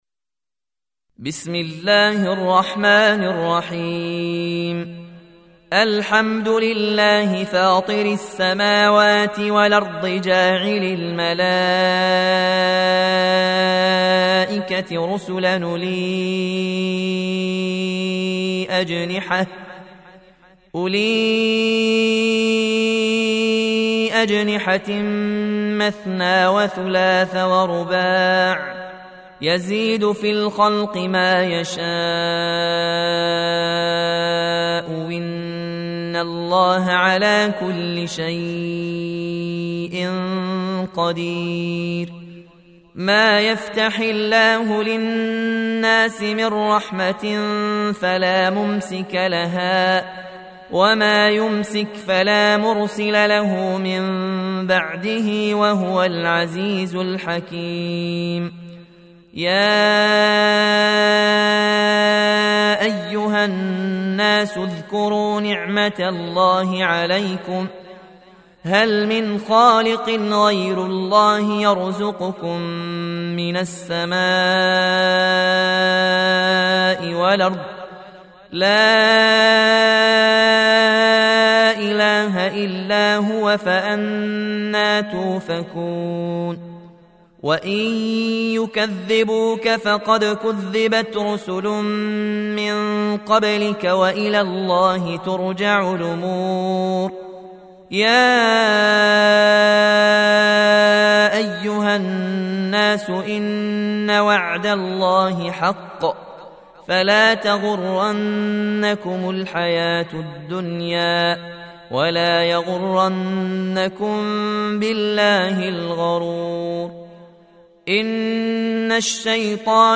Surah Sequence تتابع السورة Download Surah حمّل السورة Reciting Murattalah Audio for 35. Surah F�tir or Al�Mal�'ikah سورة فاطر N.B *Surah Includes Al-Basmalah Reciters Sequents تتابع التلاوات Reciters Repeats تكرار التلاوات